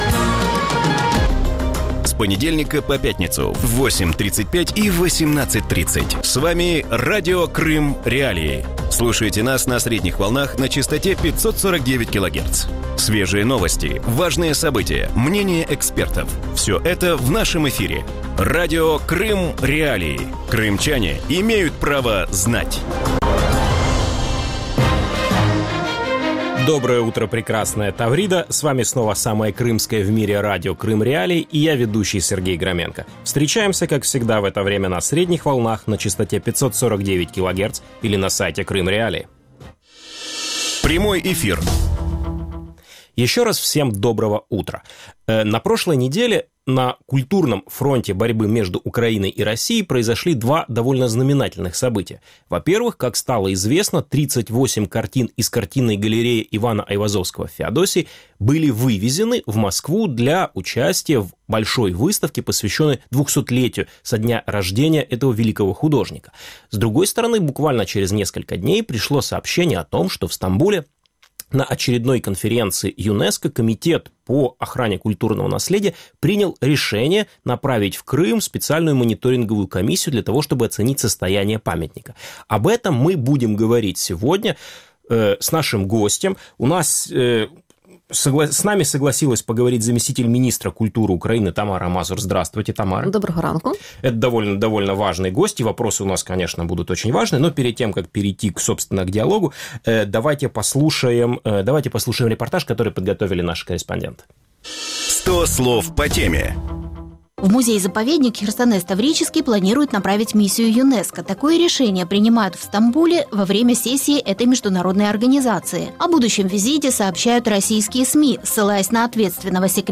Вранці в ефірі Радіо Крим.Реалії говорять про Херсонес, золото скіфів і картини Айвазовського. Після анексії Криму виникло невідворотнє питання – хто володіє культурними скарбами півострова не лише де-факто, але й де-юре?